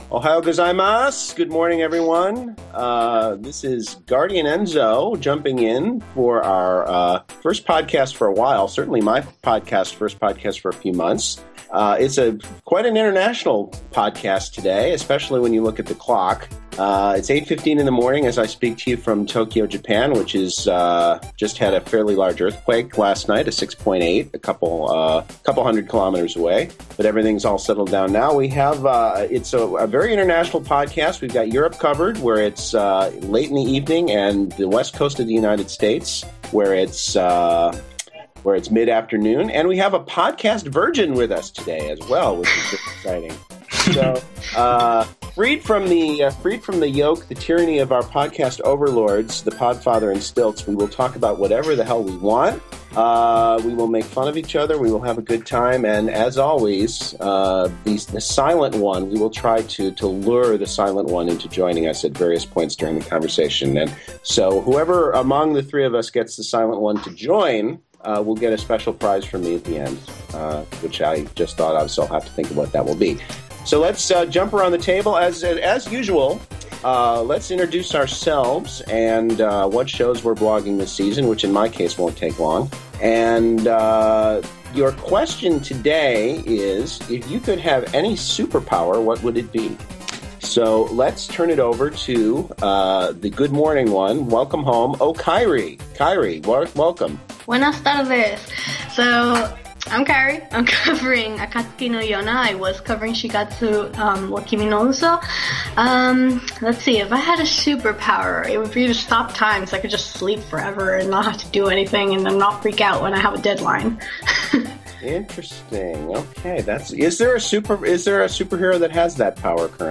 Join us for our first tri-continental podcast, as RC strikes a blow against the tyranny of distance.
MAL profile Time Index We cast a wide net with a very spontaneous conversation.